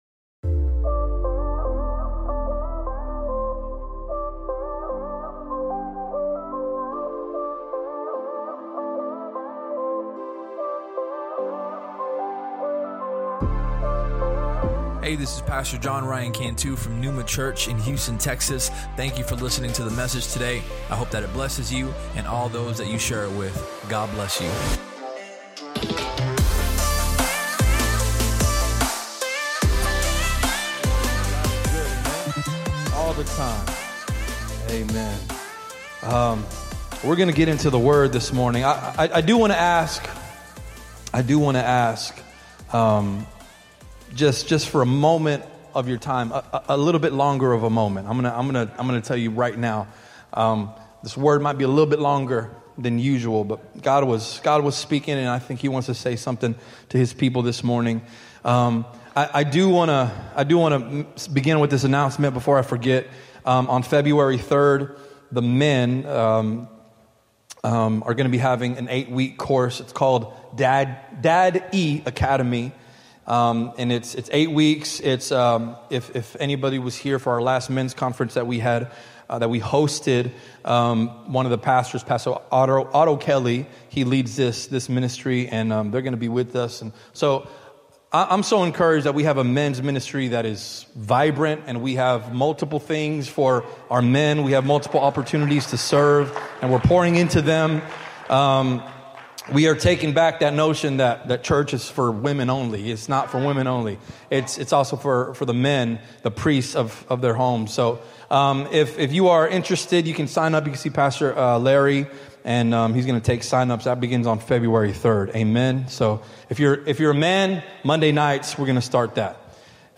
PNEUMA Church Podcast